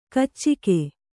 ♪ kaccike